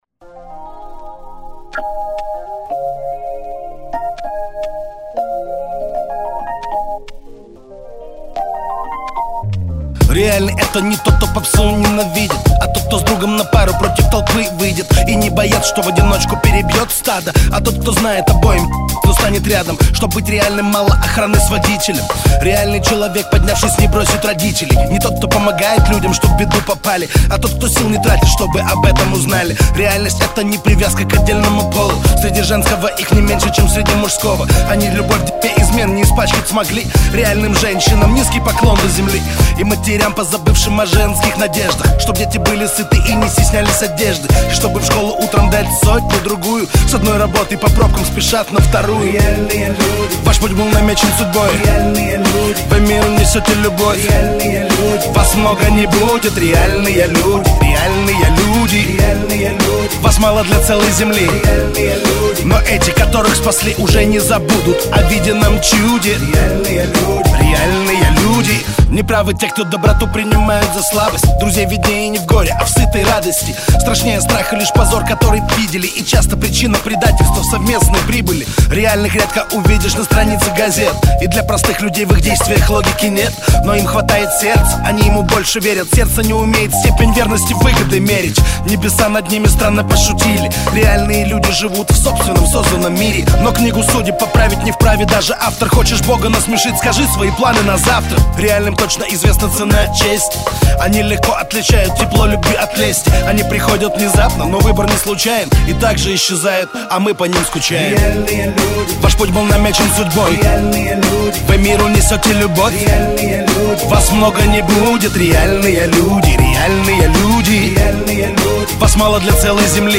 Категория: Русский реп, хип-хоп